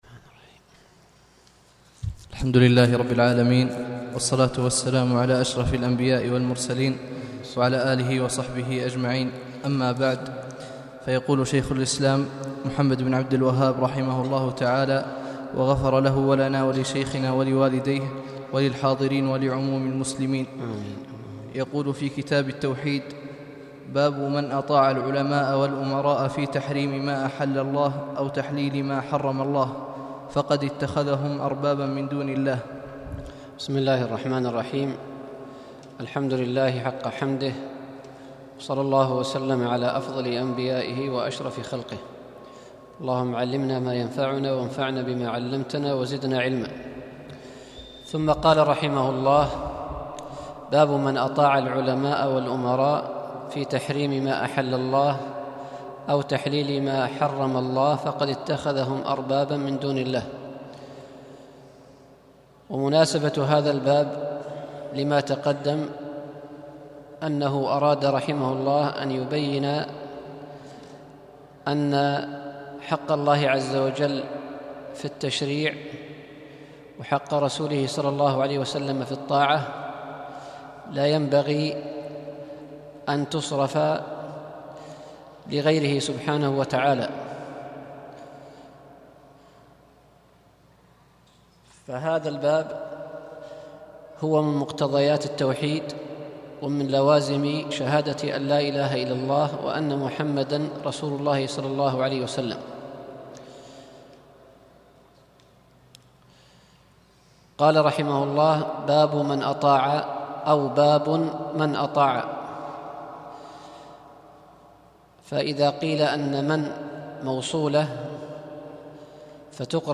الدرس ٣٦